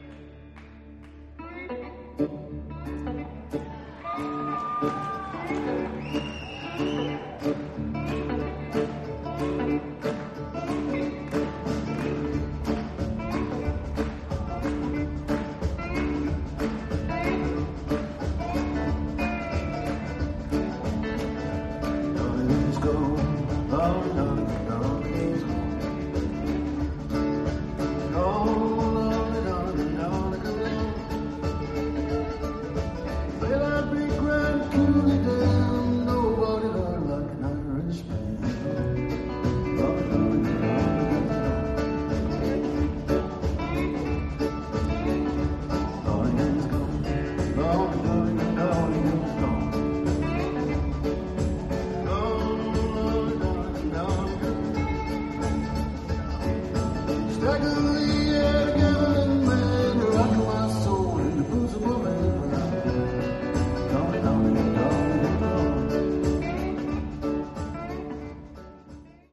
format: 2 x 5" live
Place: Kansas City, MO, USA